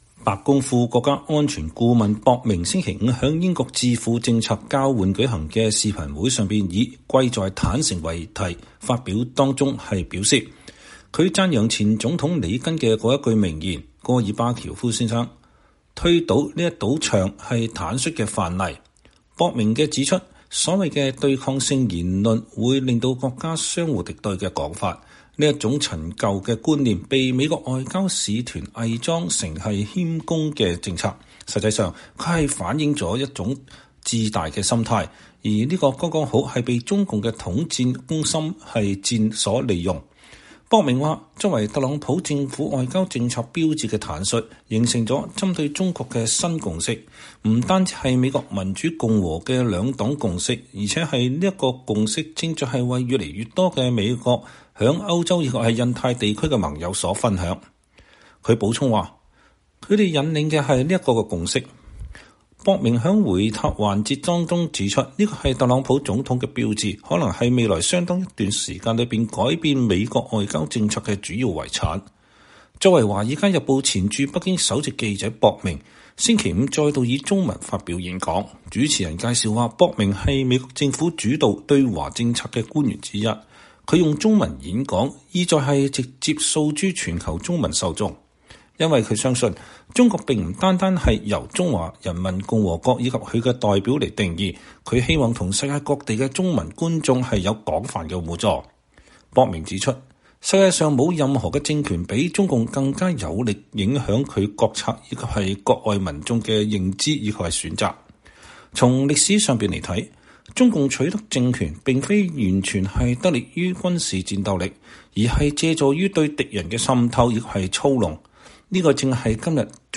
白宮副國家安全顧問博明（Matt Pottinger）星期五在英國智庫“政策交流”（Policy Exchange）舉行的視頻會上以《貴在坦誠》為題發表中文演說。